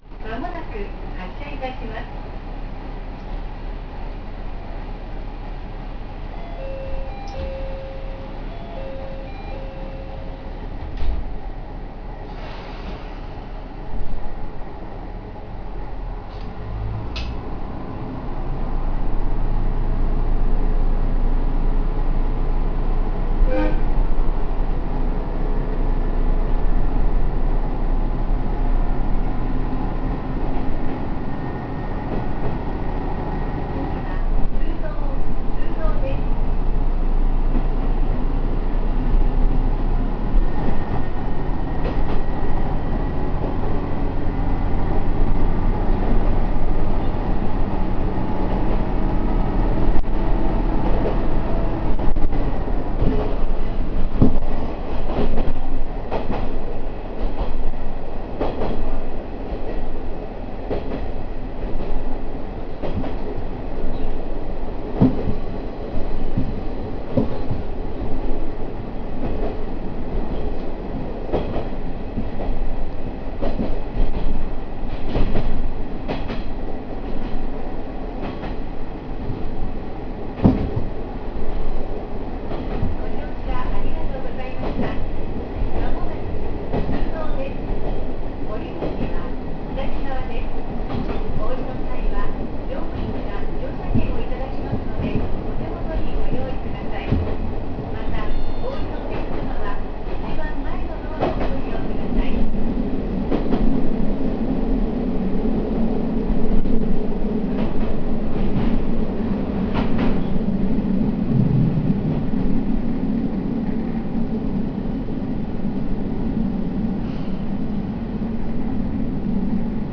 ・WKT-500形走行音
【わたらせ渓谷線】原向〜通洞（2分19秒：758KB）
ドアチャイムも走行音もごく普通の、この形のディーゼルカーによくある感じで、特別目新しさもありません。わ89形では自動放送が非常に特徴的でしたが、この車両ではごく普通の物に変更され、発車放送もなくなってしまいました。